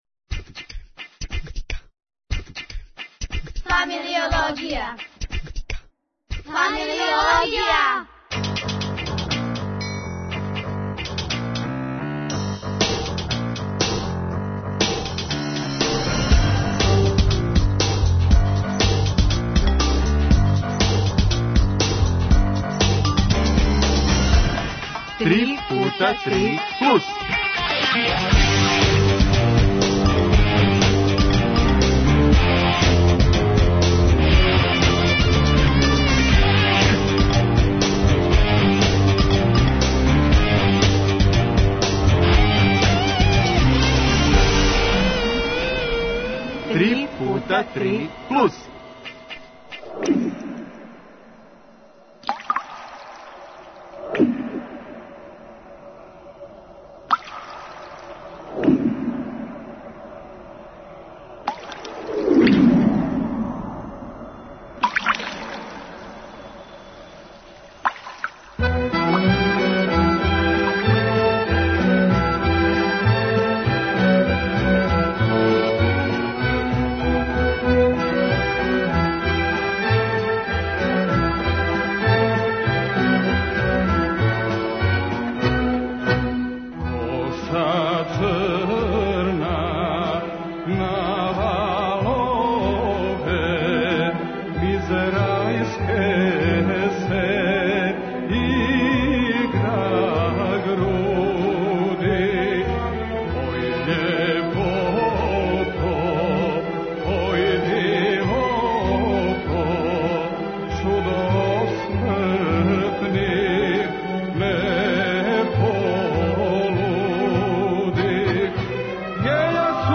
О овим и многим другим питањима разговарали смо у данашњој емисији „„3X3+".
Уместо Рзава, зачућемо и кишу